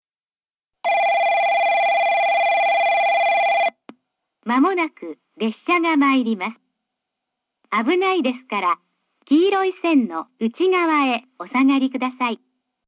2番のりば接近放送　女声
遠隔型放送A（立野行き）
スピーカーは1番のりばがTOAの灰色のラッパ型、2番のりばがカンノボックス型でしたが、現在は1番のりばはUNI-PEXクリアホーン、2番のりばはソノコラム（ミニ）に取り換えられています。